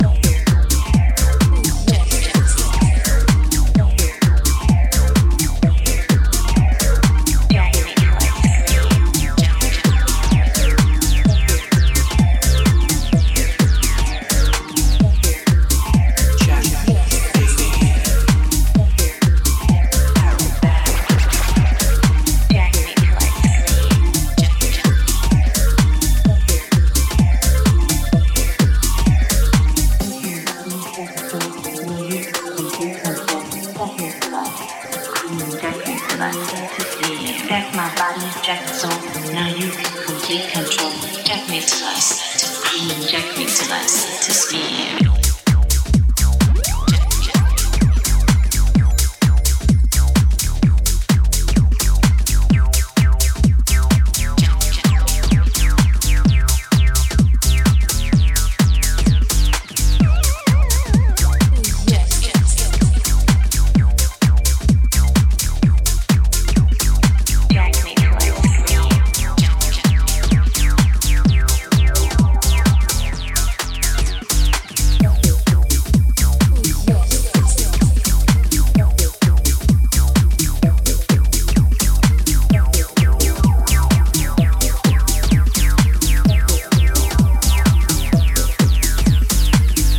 ベースライン・ハウスを鋭いアシッド・シンセやサンプルの躁気味な手数で再解釈した